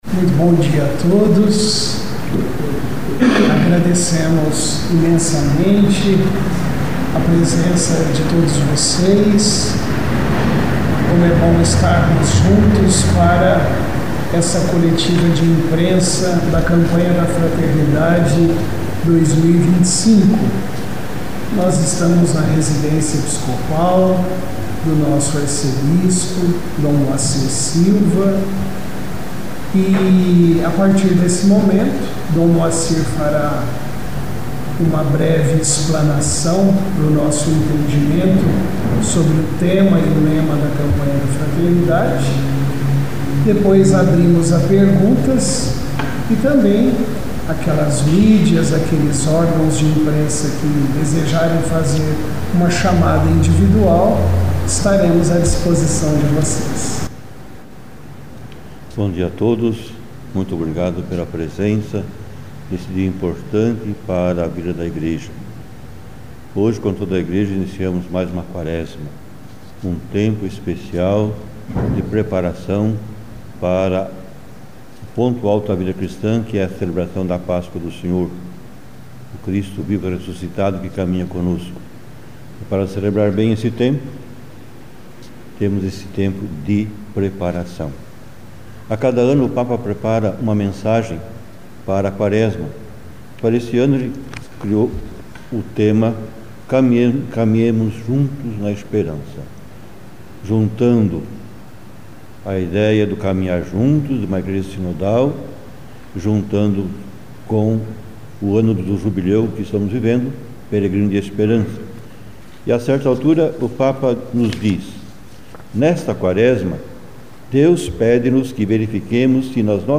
Arquidiocese realiza Coletiva de Imprensa na abertura oficial da Campanha da Fraternidade 2025
Entrevista-Coletiva-Lancamento-da-CEF-2025-integra.mp3